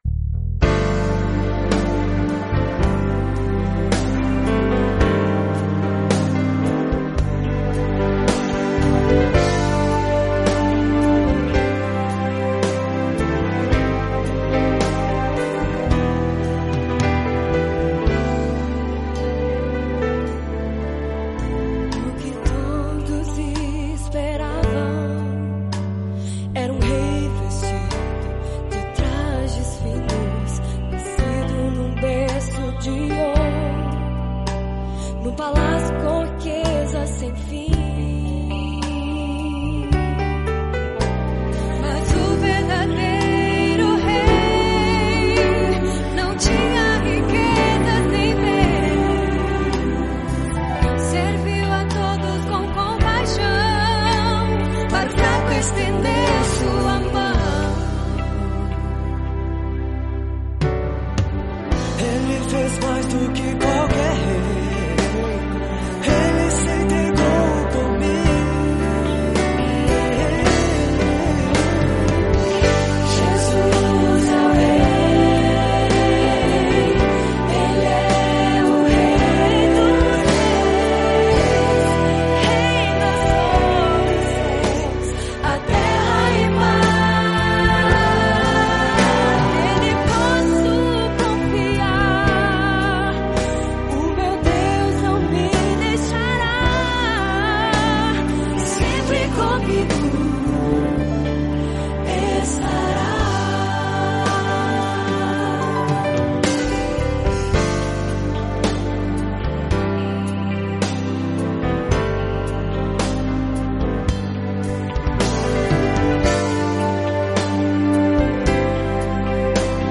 Aqui, nesta breve reflexão, que repeti no microfone da Rádio Você, de nossa cidade, eu falo sobre alguns bons amigos que tenho e sobre uma nova concepção do que seja 'amigo'.
Em diversas oportunidades, participei, ao vivo, de programas da 'Rádio Você', emissora que fazia parte do grupo jornalístico 'O Liberal', de nossa cidade, Americana.